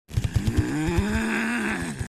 发怒火.MP3